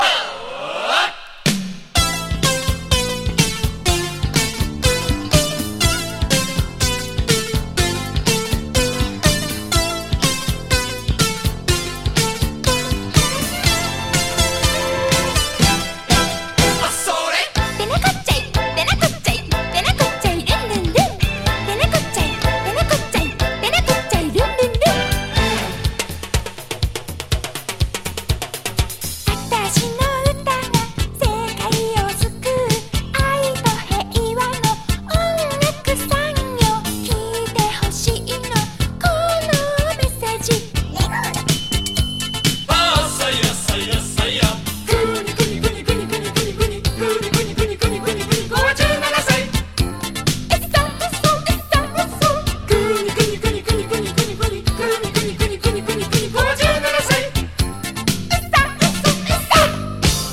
コミカル・テクノポップ。